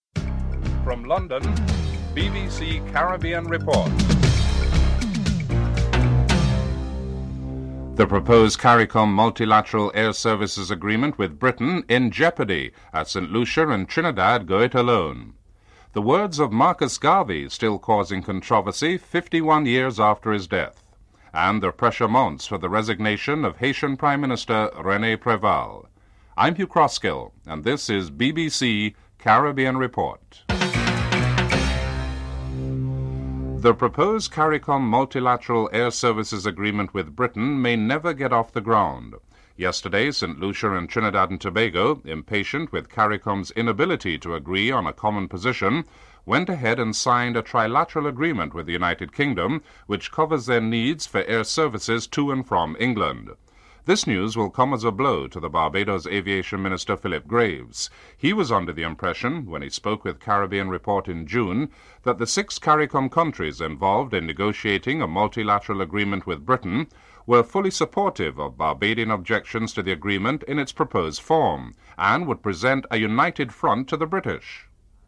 The British Broadcasting Corporation
1. Headlines (00:00-00:32)
Comments from Toby Harris, leader of the Haringey Council (10:21-13:57)